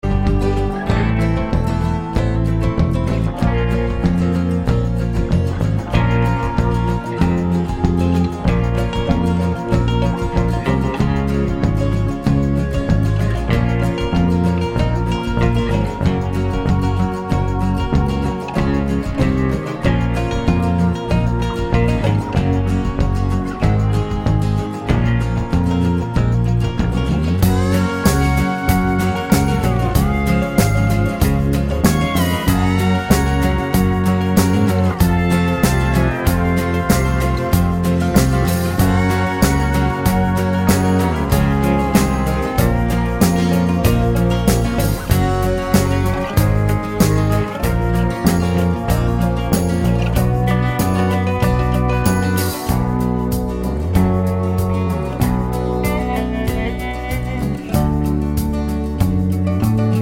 no Backing Vocals Country (Male) 3:12 Buy £1.50